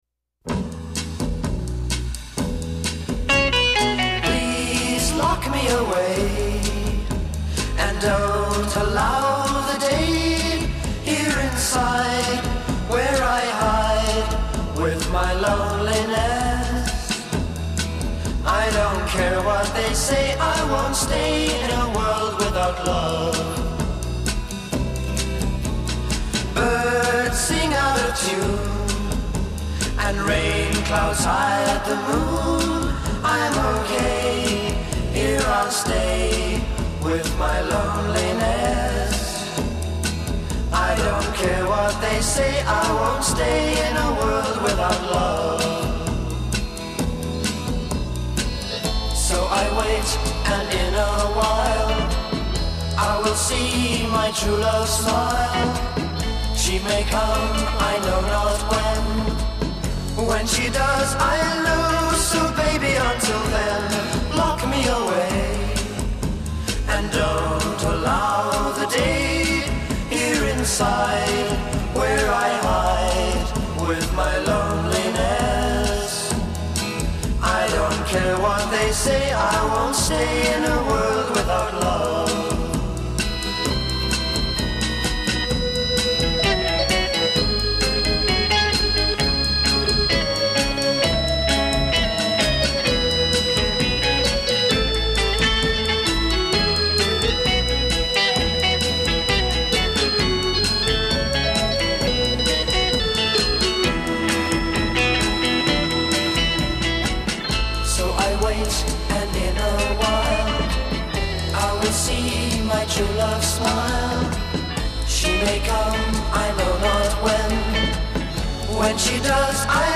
Recorded on 21 January 1964 at EMI Recording Studios
electric twelve-string guitar
Intro 0:00 4 Mersey-beat drums & bass emphasis
A Verse : 16+8 organ and guitar alternation on solo
[organ plays main melody; guitar plays against it]
Coda : 13 repeat hook and end with triplets a'